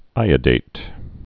(īə-dāt)